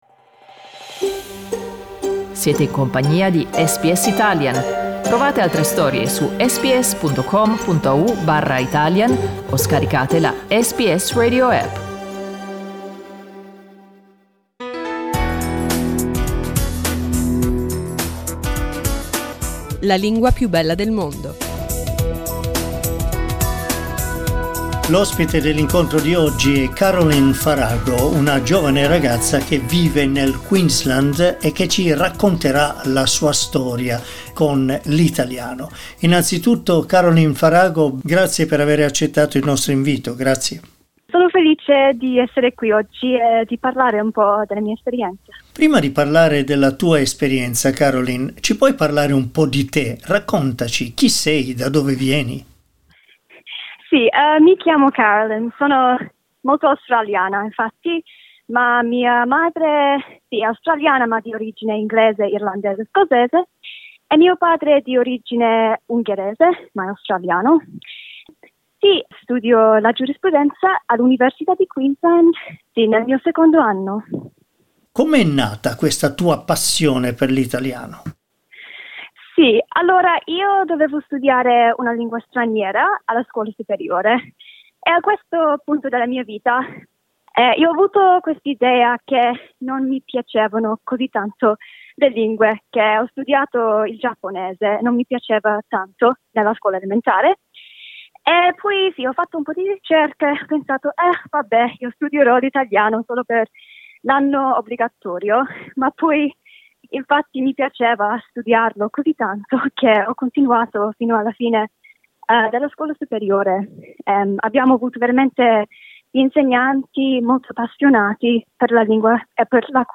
SBS Italian